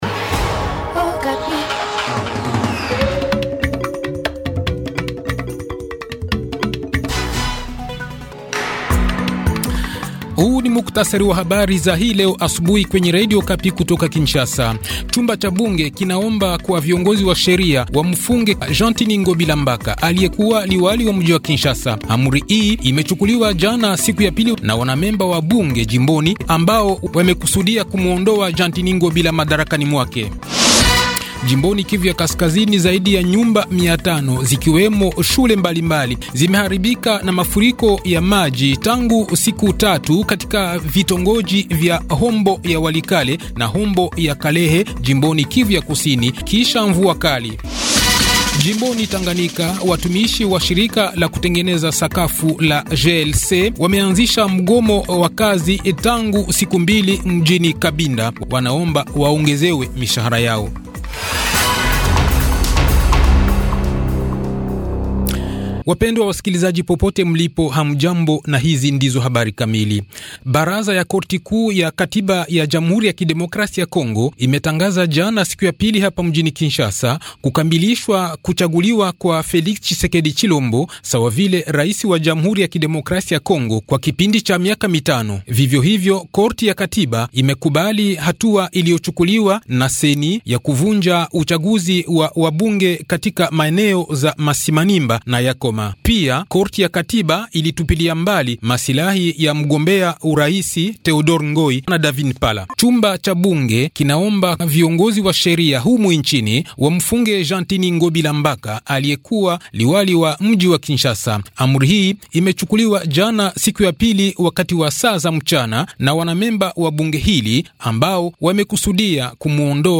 Le journal Swahili de 5h 10 Janvier 2024